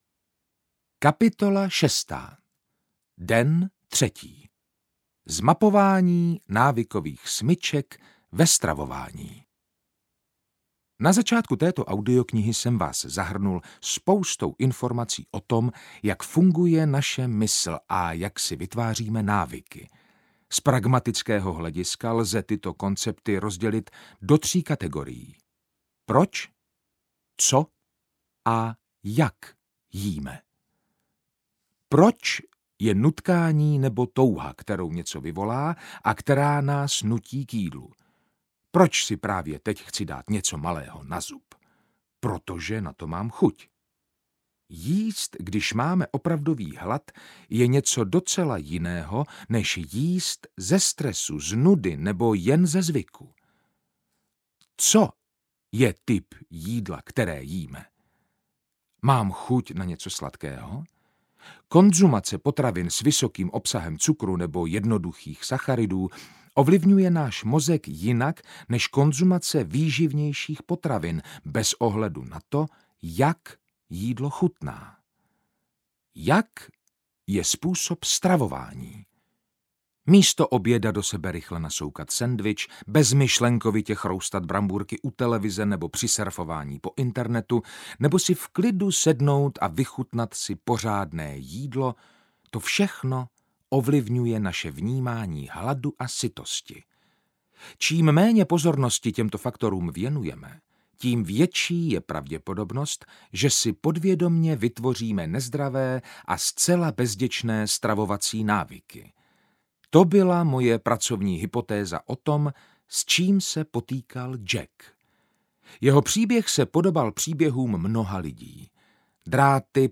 Pseudohlad audiokniha
Ukázka z knihy